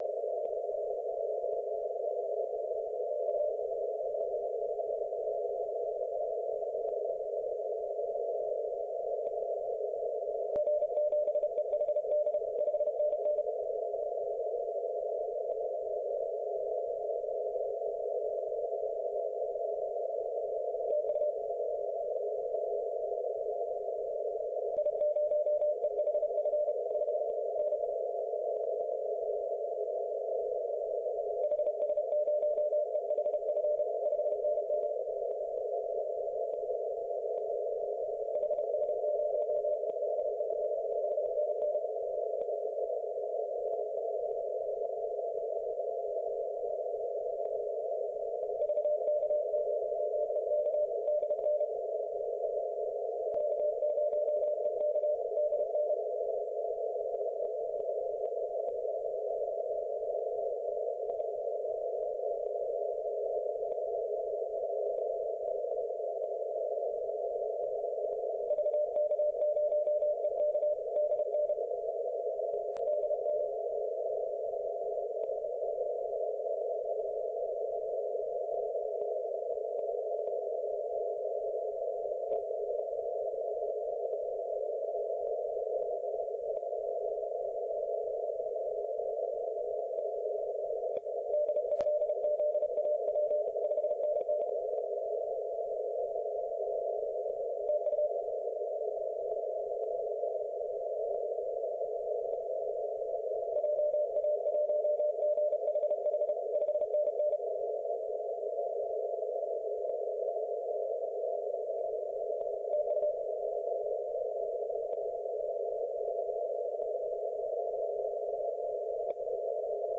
Až nyní jsem zjistil, že jsem zapomněl zapnout audionahrávání, ale nevadí, pro ilustraci jsem nahrál signál VK0EK chvilku poté, je možné toto audio stáhnout